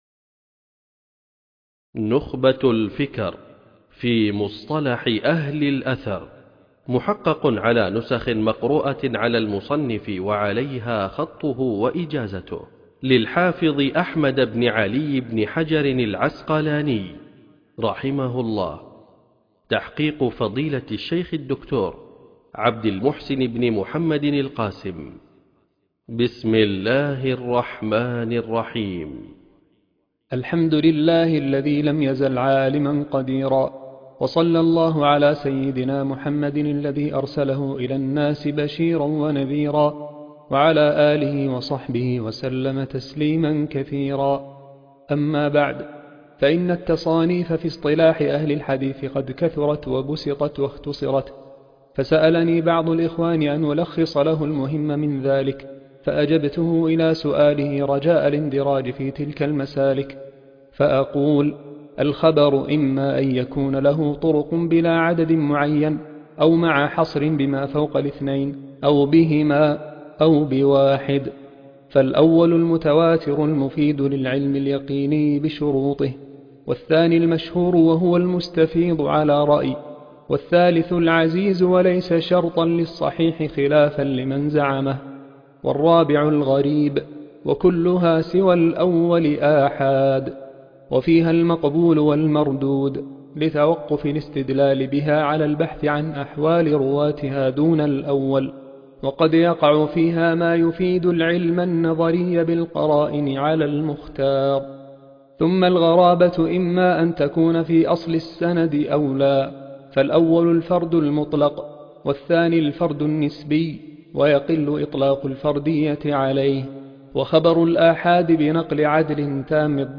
نخبة الفكر في مصطلح أهل الأثر قراءة